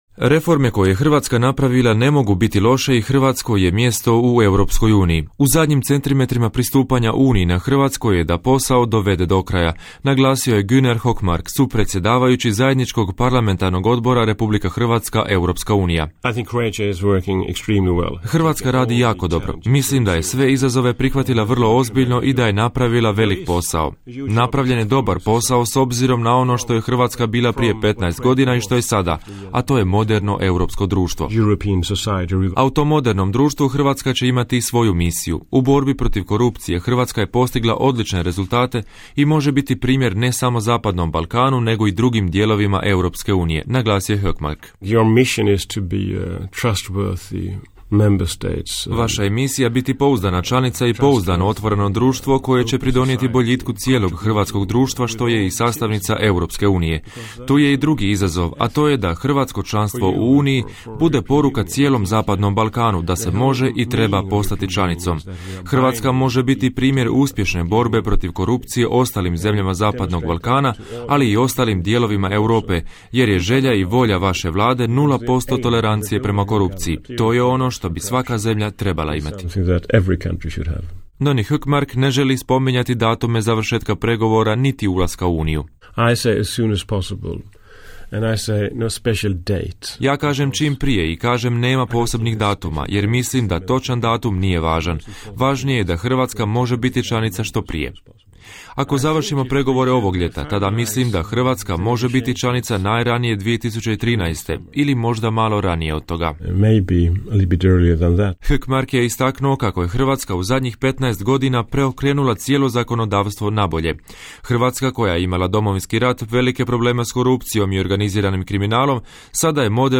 Intervju i kroatisk radio